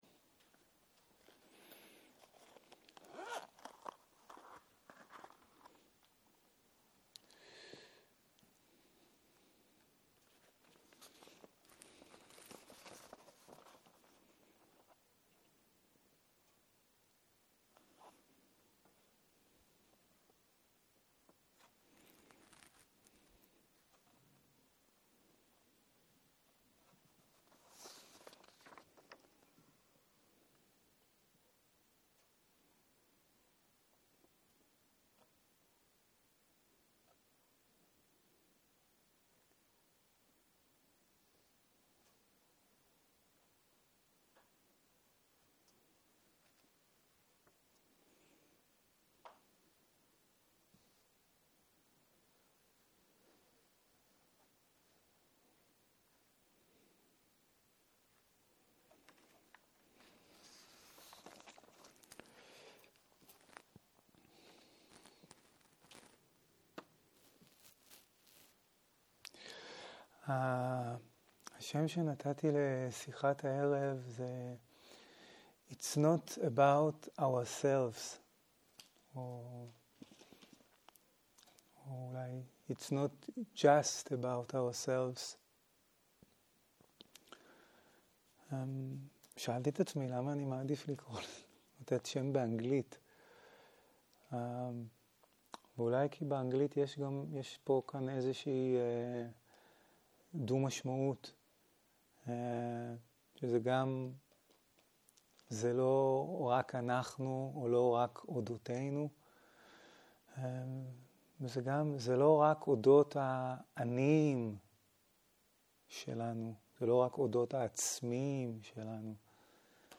18.01.2023 - יום 6 - ערב - שיחת דהרמה - Self - reflective it's not about ourself - הקלטה 13